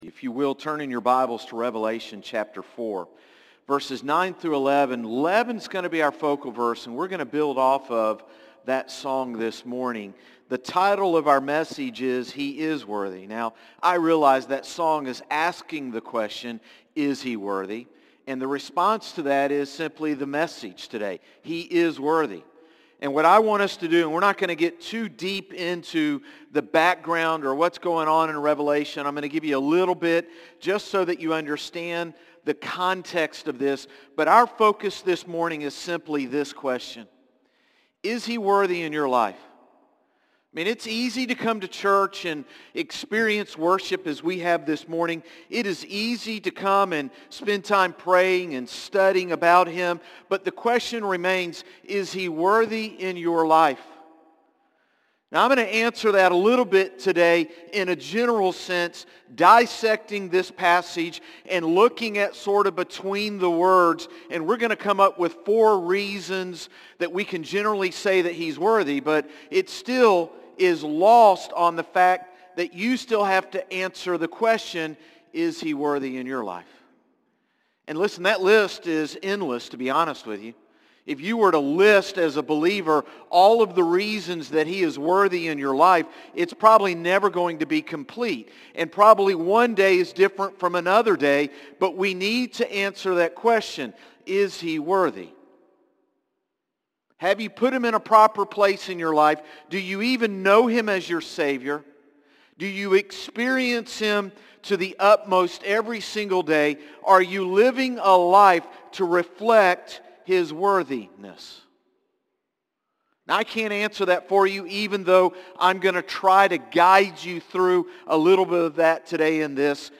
Sermons - Concord Baptist Church
Morning-Service-4-7-24.mp3